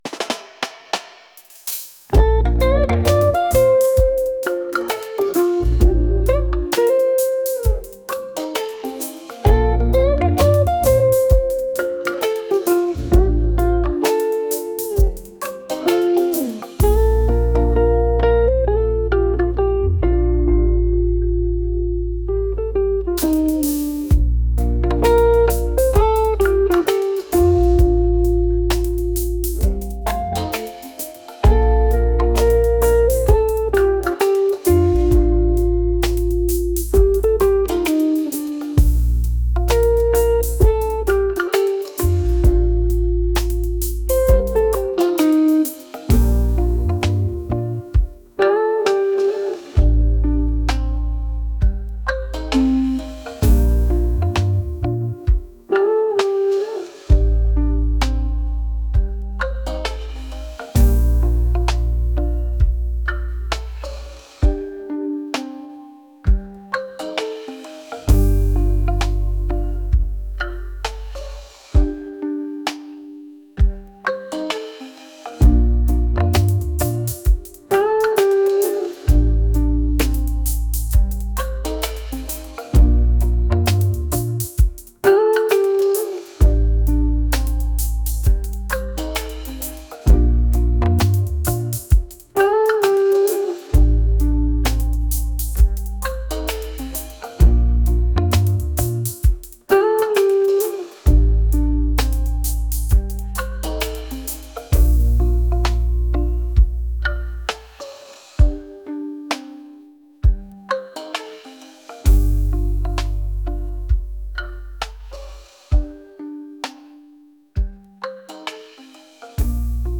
reggae | smooth